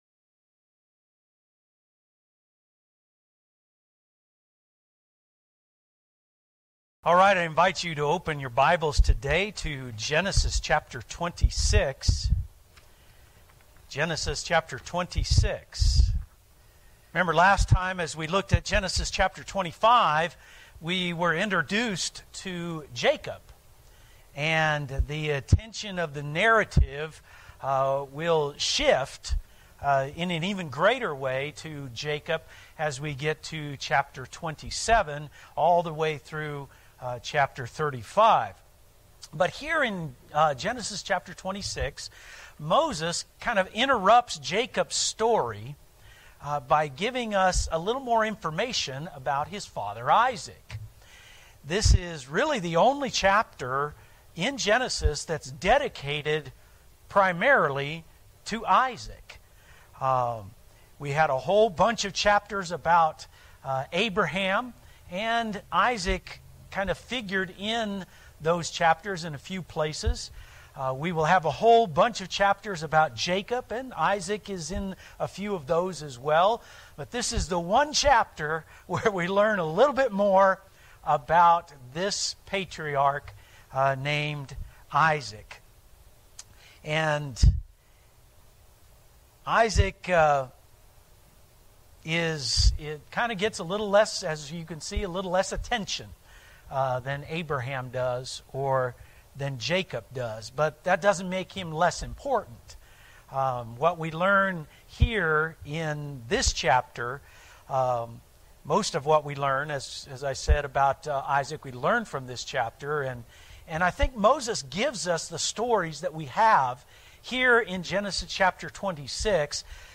Sermons Archives - Page 16 of 41 - New Covenant Baptist Church